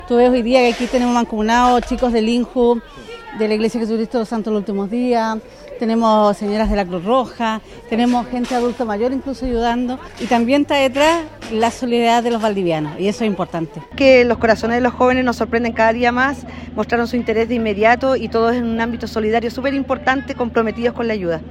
Distintas organizaciones participaron también de esta acción, según detalló a Radio Bío Bío la monitora de una organización cristiana involucrada, y la representante de un grupo scout también partícipe.